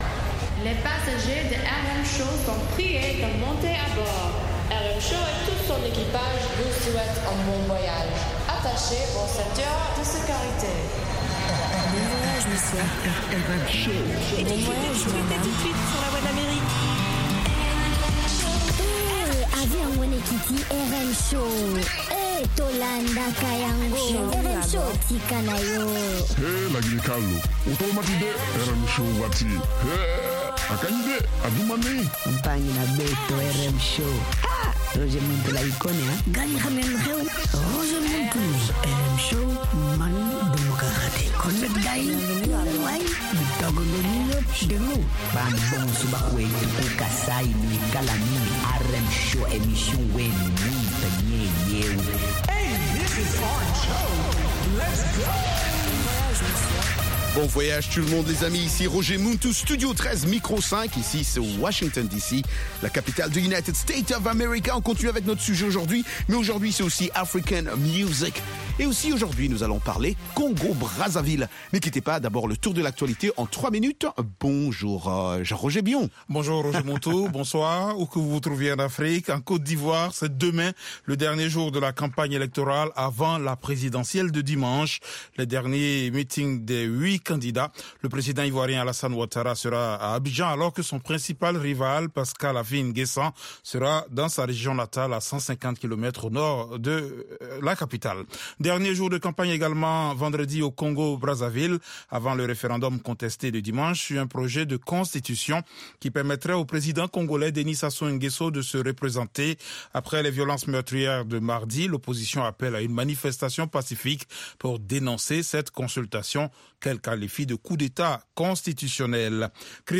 propose notamment d'écouter de la musique africaine
interviews